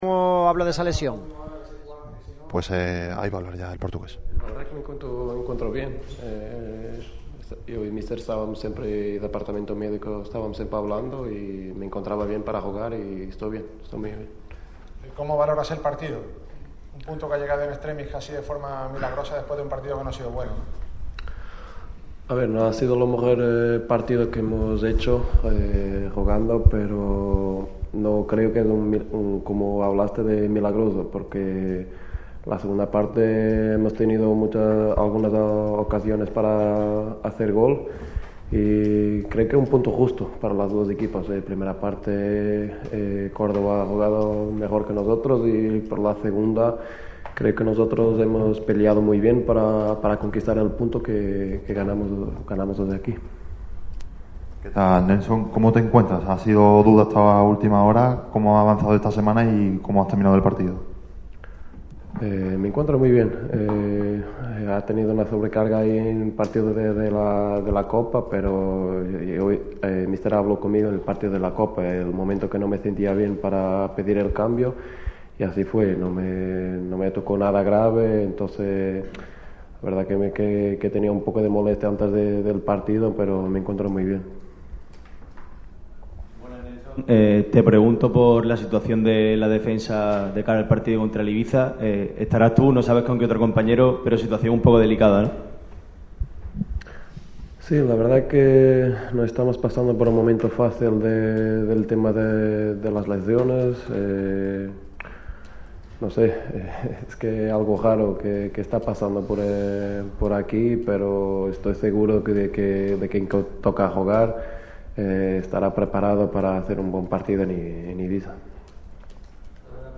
Al término del partido ante el Córdoba pasó por sala de prensa Nélson Monte, que fue duda hasta última hora y finalmente jugó todo el encuentro. El portugués valoró de forma positiva el punto frente a los cordobesistas.